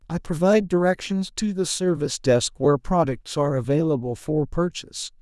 TTS_audio / PromptTTS++ /sample2 /Template2 /Condition /Customer /Emotion /angry /peeved.wav
peeved.wav